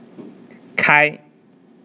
open_sound.wav